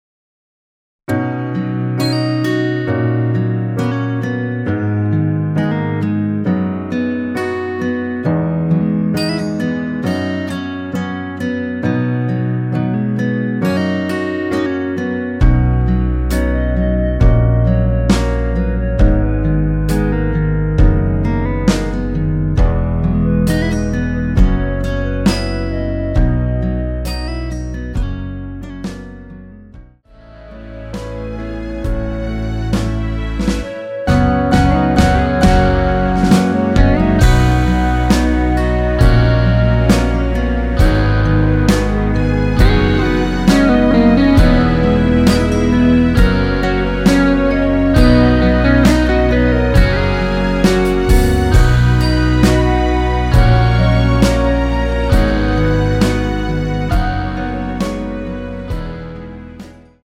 원키에서(+3) 올린 멜로디 포함된 MR 입니다.(미리듣기 참조)
앞부분30초, 뒷부분30초씩 편집해서 올려 드리고 있습니다.
중간에 음이 끈어지고 다시 나오는 이유는